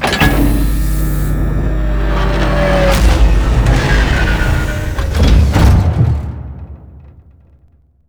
land.wav